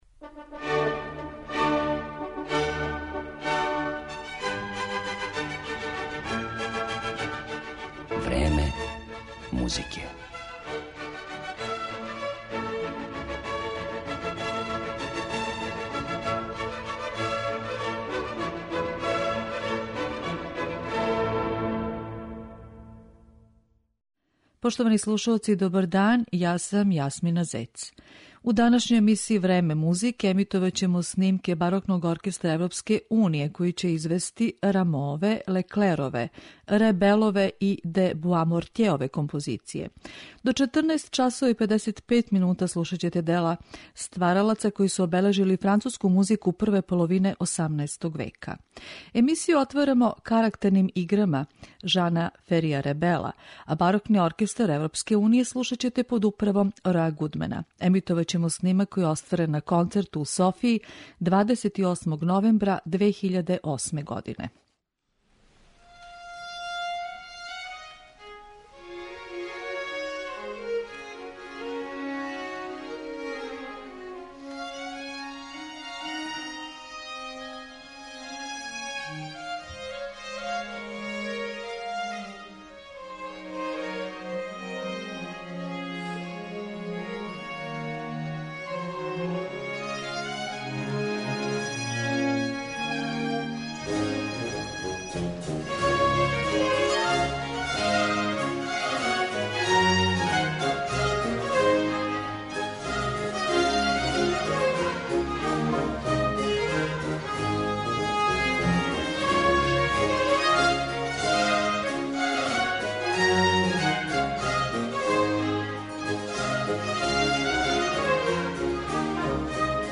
У емисији 'Време музике', емитоваћемо снимке Барокног оркестра Европске уније
У интерпретацији овог изврсног ансамбла, слушаоци ће моћи да чују дела: Ребела, Леклера и Рамоа, стваралаца који су обележили прву половину 18. века у француској музици.
Замишљен као покретни конзерваторијум, овај ансамбл сваке године окупља младе талентоване музичаре из Европе који одређен програм изводе на бројним путовањима.